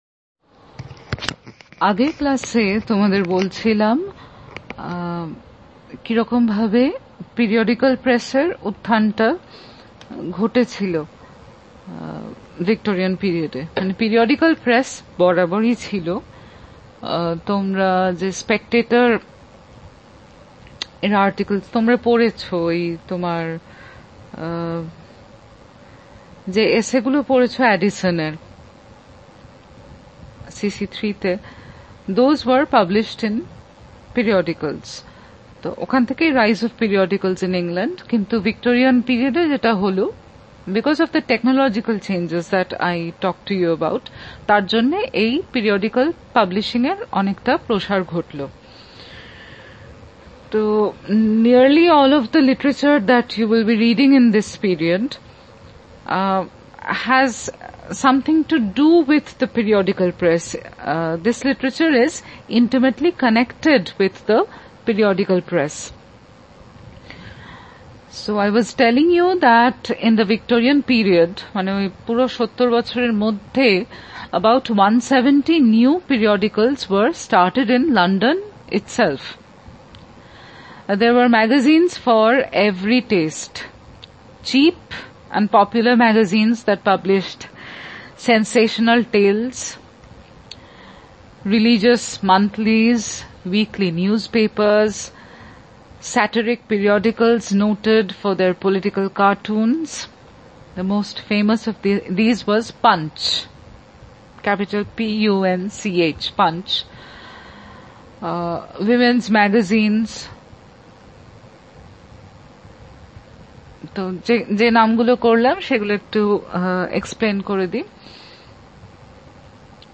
DB Lec 4 Literacy, Publication, Reading 2.mp3